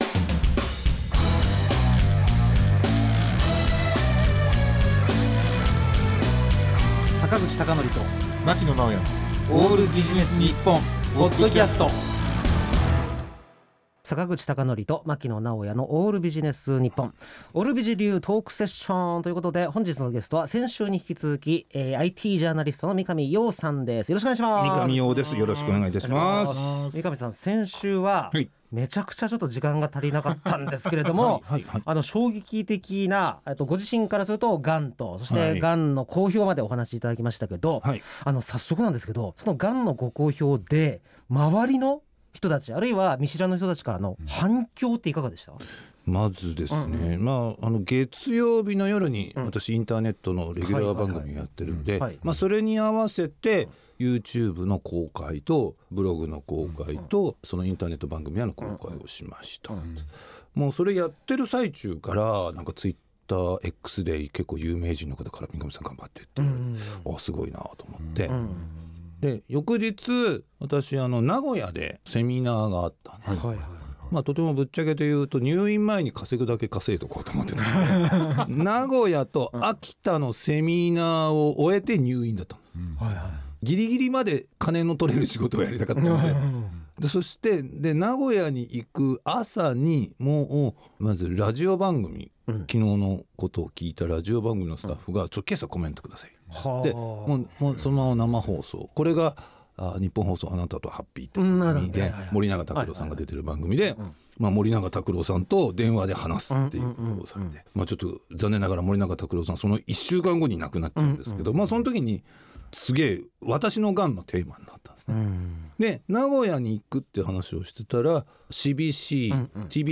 O.Aより（FM世田谷83.4MHz）